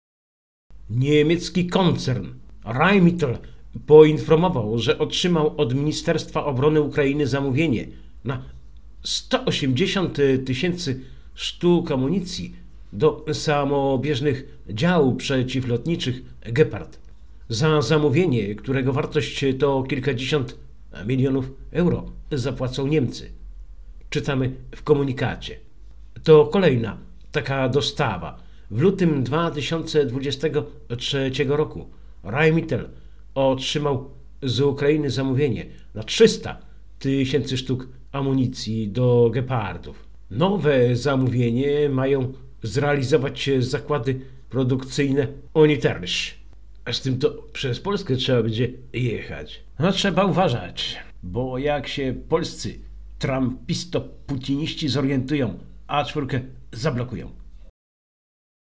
W Radiu Maria od początku pełnoskalowego konfliktu w Ukrainie, w samo południe nadajemy audycję „Pół godziny dla Ukrainy”. Stałym elementem jest hymn Ukrainy, gdzie w tle słychać odgłosy walk i informacje z frontu.